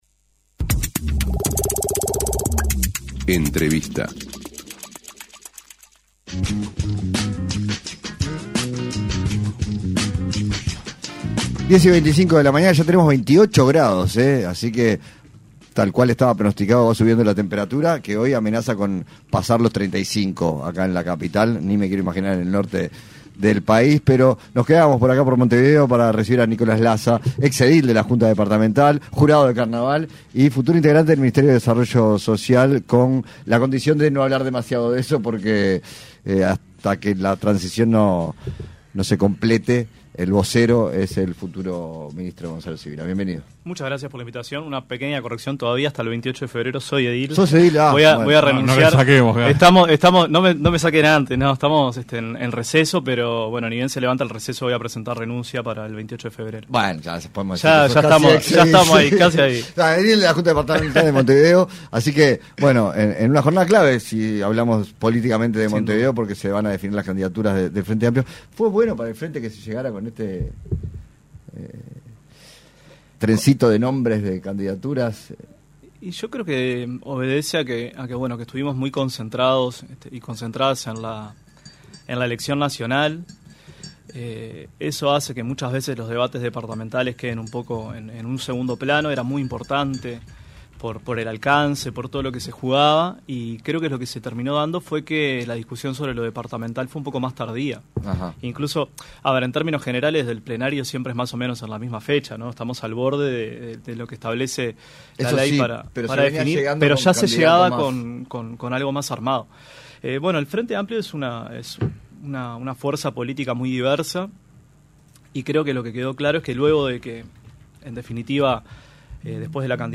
Entrevista a Nicolás Lasa (Edil del FA y presidente del Jurado de Carnaval)